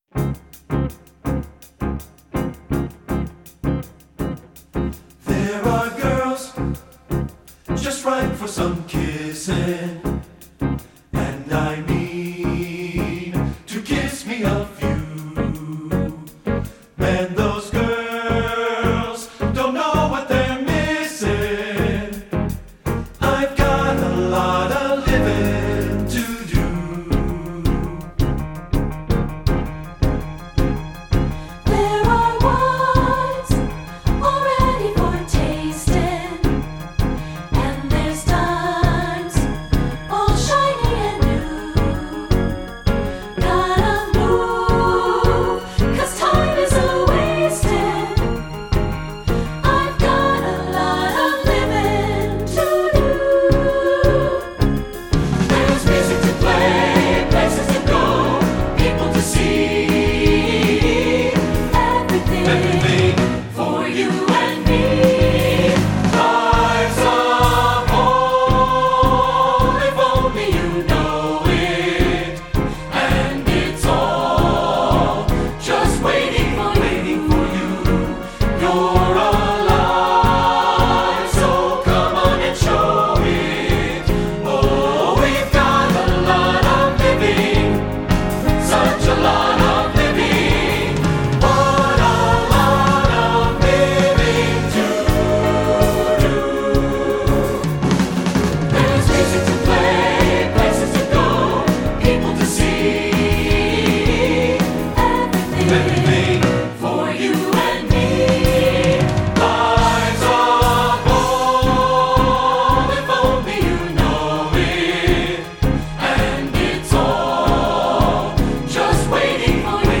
Instrumentation: choir (SSA: soprano, alto)
choral